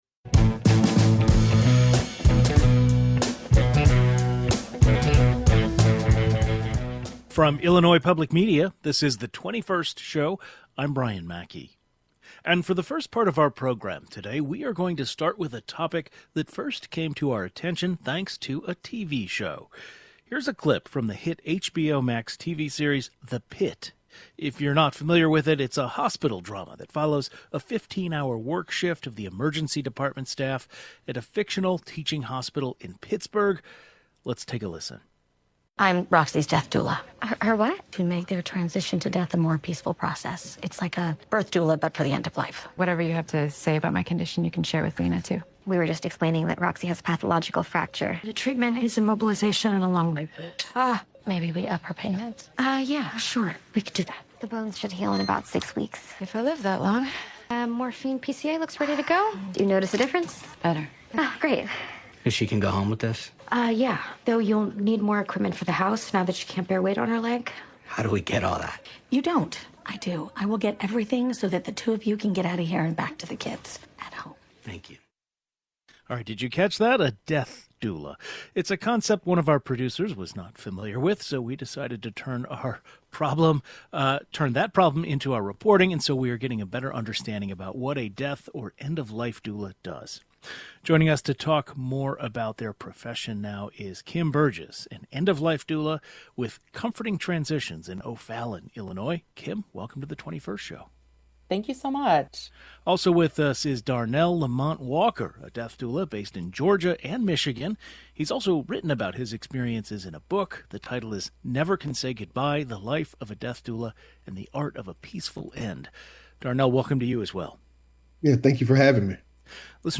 But there are also doulas whose work focuses on the opposite end of our lifespans. They’re called death or end-of-life doulas. We’ll talk with two of them about their work.